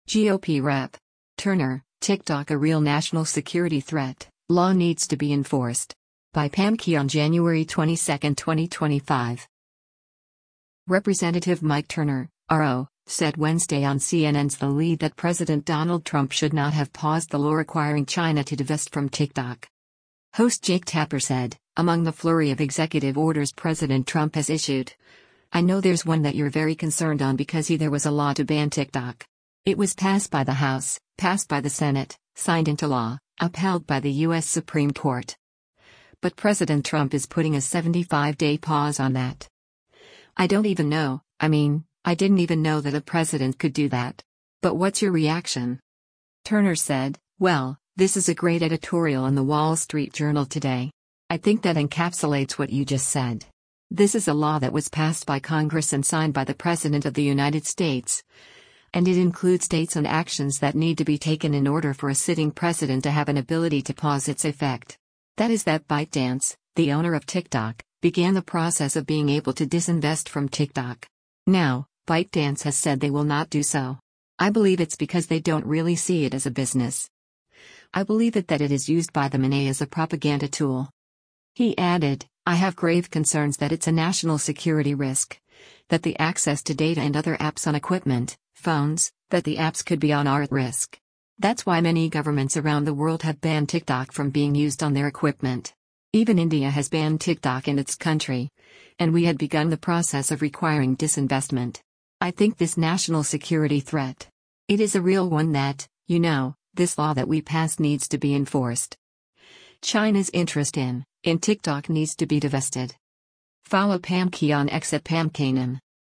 Representative Mike Turner (R-OH) said Wednesday on CNN’s “The Lead” that President Donald Trump should not have paused the law requiring China to divest from TikTok.